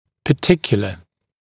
[pə’tɪkjələ] [-kju-][пэ’тикйэлэ], [-кйу-]особый, частный, специфический; определённый, индивидуальный, отдельный